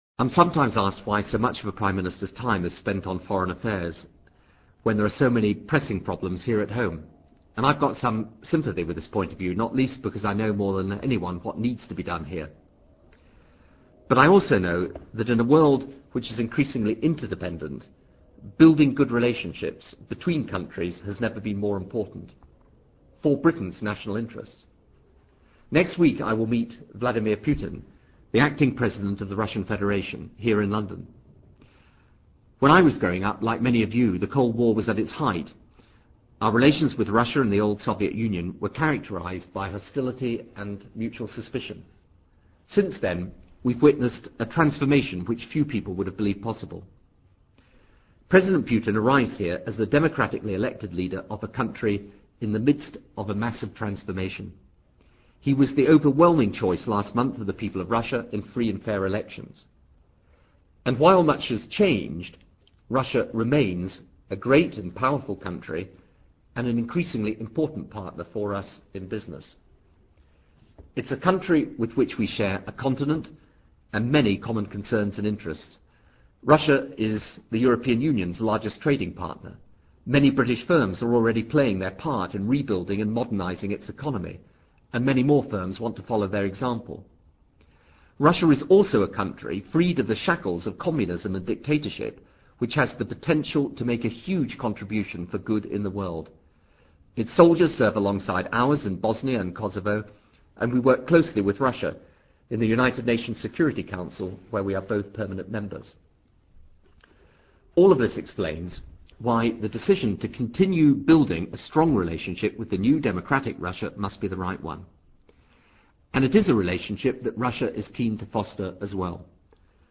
PRIME MINISTER'S INTERNET BROADCAST, 13 APRIL 2000 - RELATIONS WITH RUSSIA